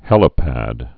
(hĕlə-păd)